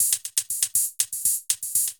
UHH_ElectroHatD_120-01.wav